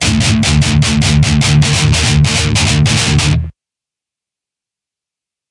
金属吉他循环播放 未修剪的150BPM " DIST GUIT 150BPM 10 - 声音 - 淘声网 - 免费音效素材资源|视频游戏配乐下载
金属吉他环没有一个被修剪过。它们都是440 A，低E在150BPM下降到D所有